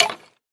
skeletonhurt1